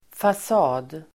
Uttal: [fas'a:d]